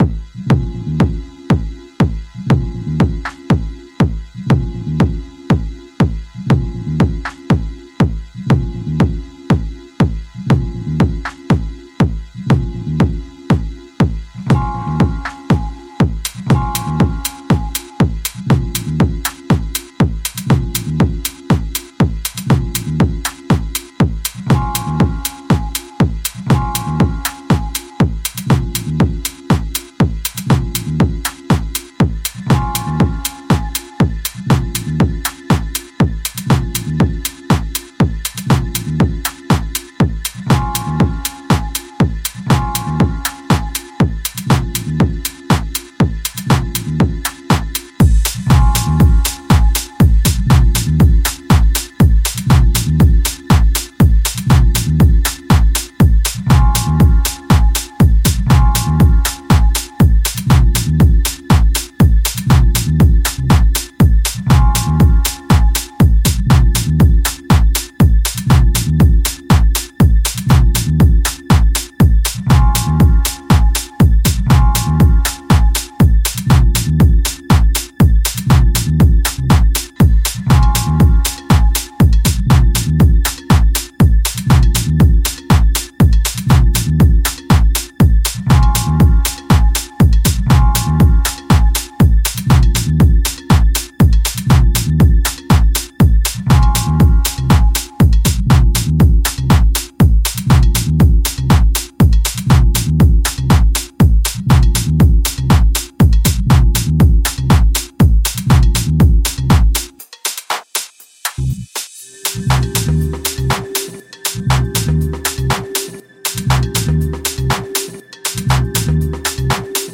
pure house sound
the production is minimalistic and deep at the same time.